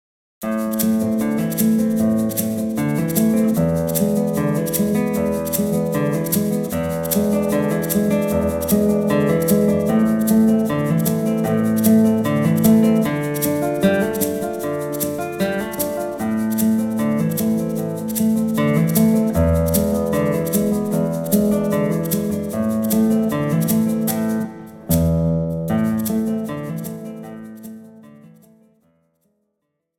Besetzung: Gitarre